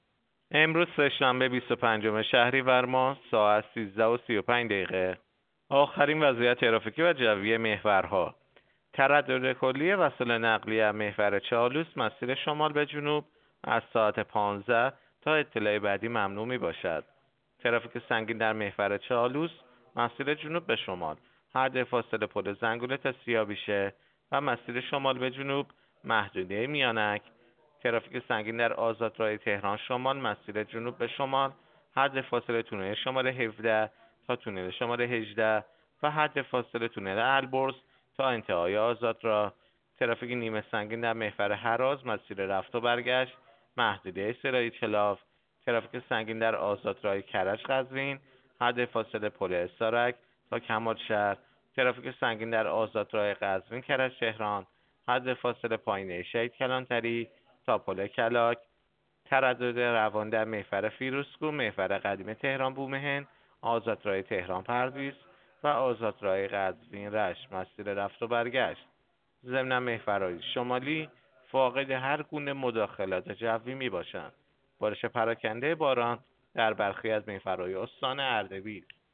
گزارش رادیو اینترنتی از آخرین وضعیت ترافیکی جاده‌ها ساعت ۱۳:۳۵ بیست و پنجم شهریور؛